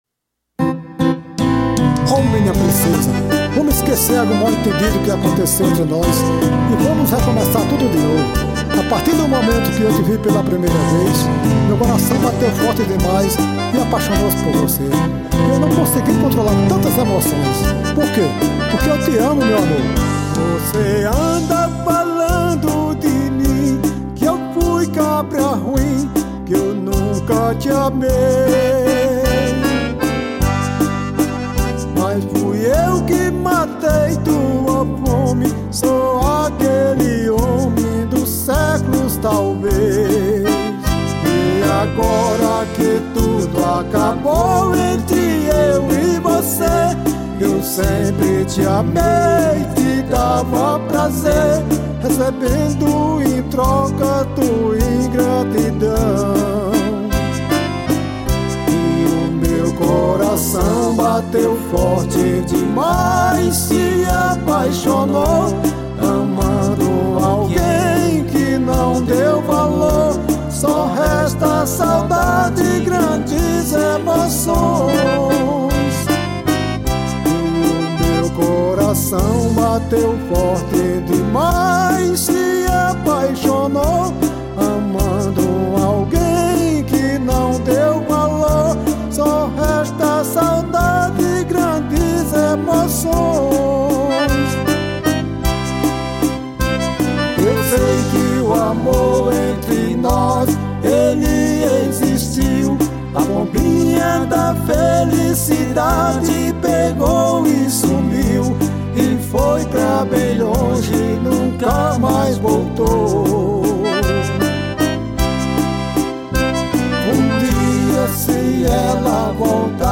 EstiloBrega